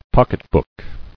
[pock·et·book]